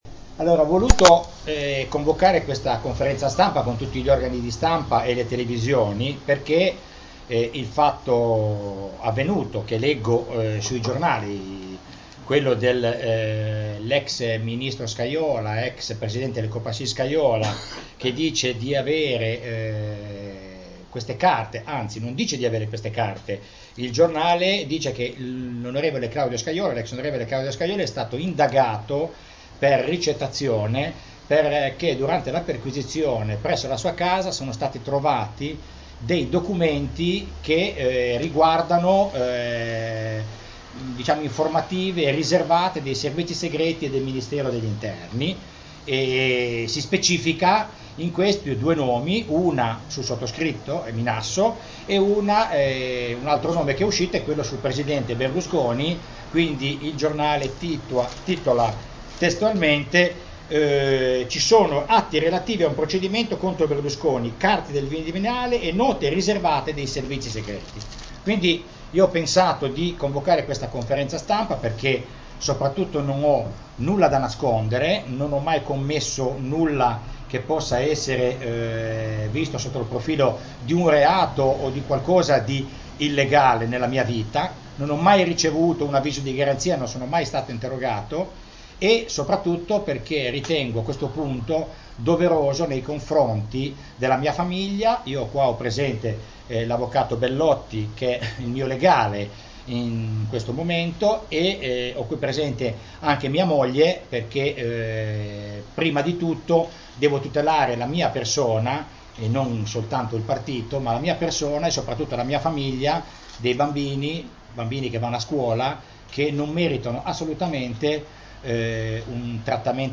Conferenza_Stampa_Eugenio_Minasso.MP3